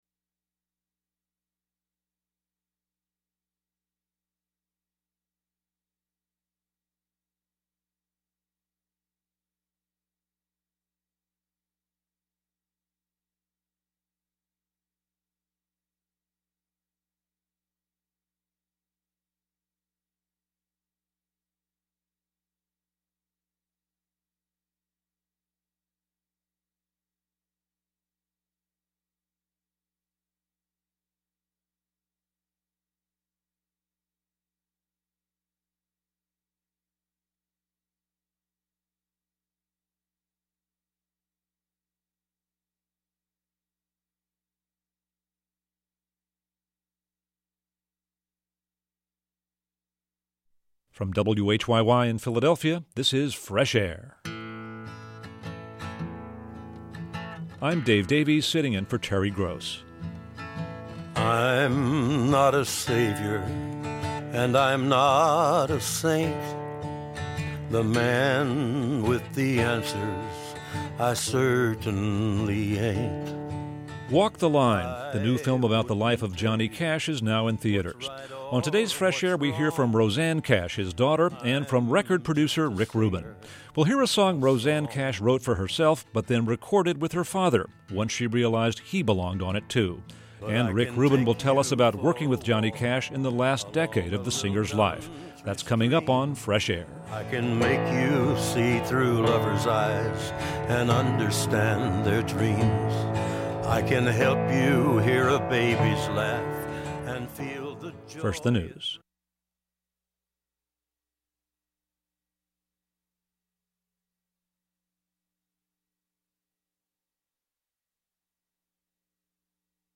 Producer Rick Rubin on the Man in Black's Legacy | Fresh Air Archive: Interviews with Terry Gross
Record Producer Rick Rubin